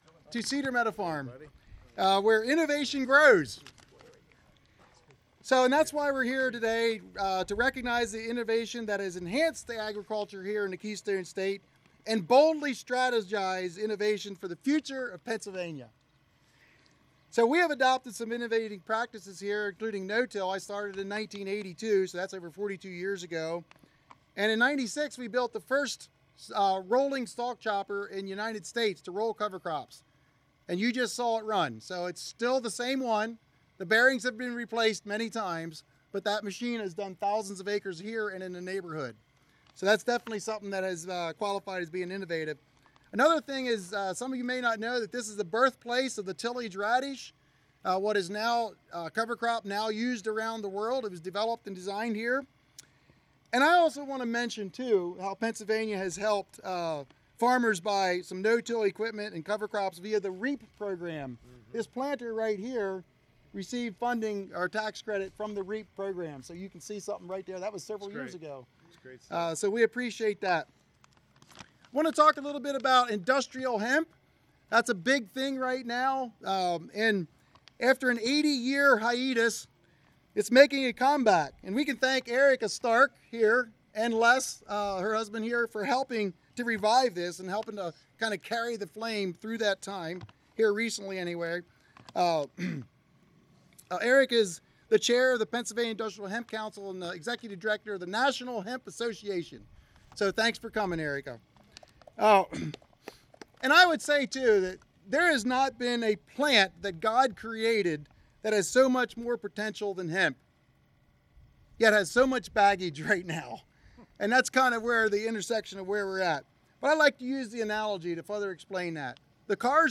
During Visit to Lancaster County Farm, Governor Shapiro and Secretary Redding Highlight How Proposed $10.3 Million Agriculture Innovation Program Will Support Pennsylvania Farmers